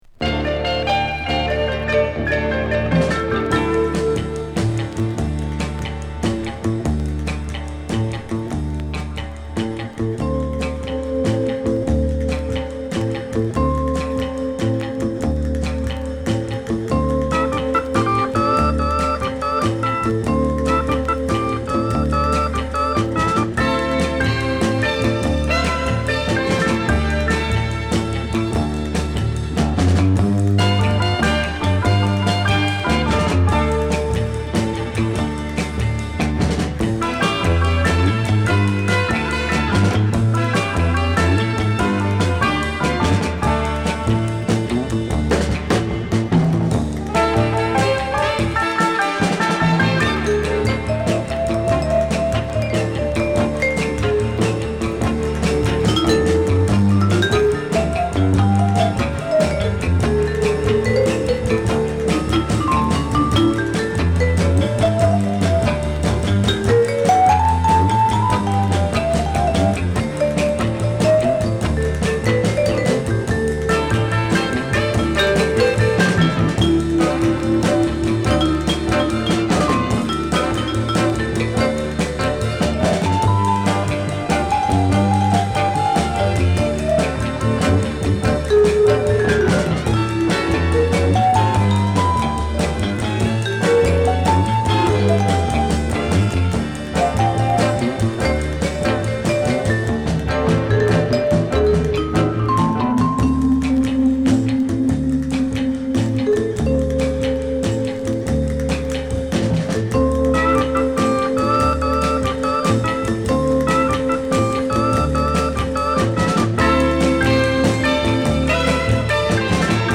ヴィブラフォン奏者